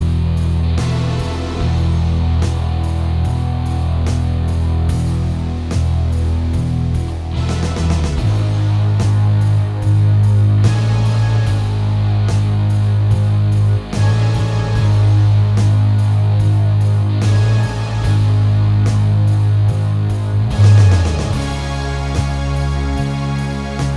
Minus Solo Electric And Solo Sp Rock 6:28 Buy £1.50